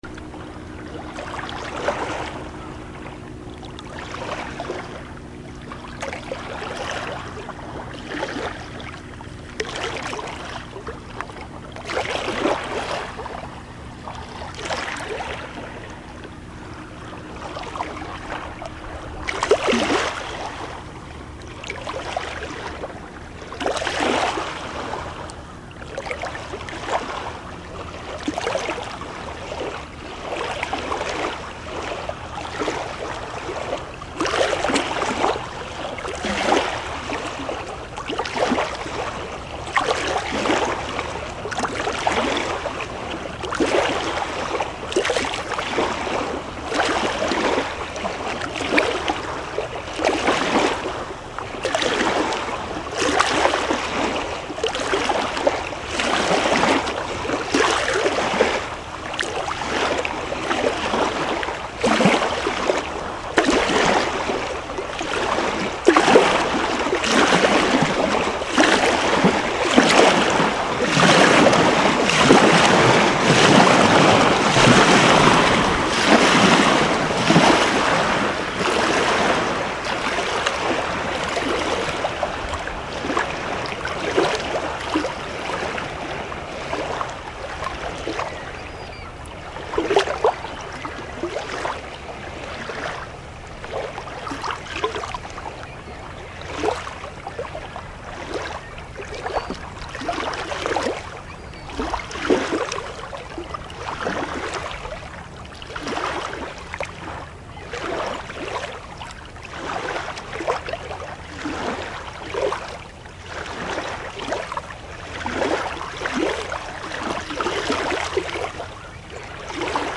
现场录音 " 湖
描述：这段录音来自我在MS参观的一个湖，叫Sardis湖。我站在水边，一艘船从大约500英尺外经过，船产生的波浪慢慢到达岸边。用我的索尼手持录音机录制的。
标签： 平静 晚上 fieldrecordings 地理标记 湖泊 自然 岩石 萨迪斯
声道立体声